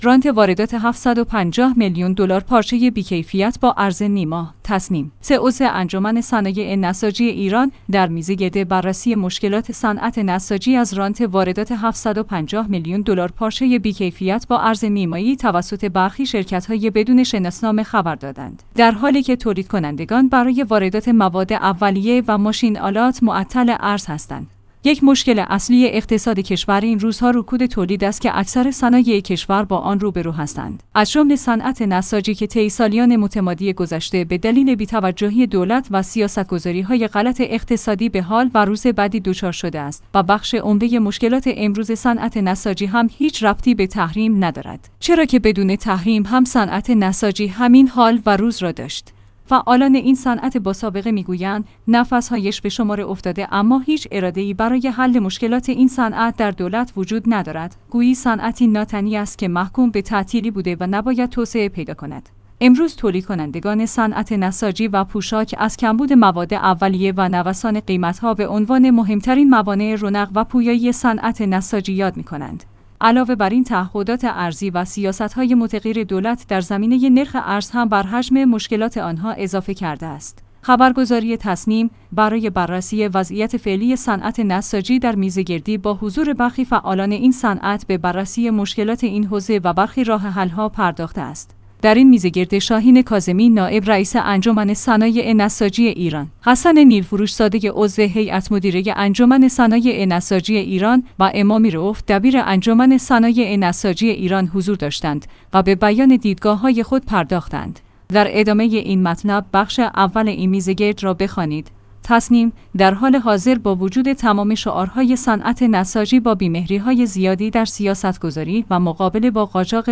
تسنیم/سه عضو انجمن صنایع نساجی ایران در میزگرد بررسی مشکلات صنعت نساجی از رانت واردات ۷۵۰ میلیون دلار پارچه بی کیفیت با ارز نیمایی توسط برخی شرکتهای بدون شناسنامه خبر دادند، در حالی که تولیدکنندگان برای واردات مواد اولیه و ماشین آلات معطل ارز هستند.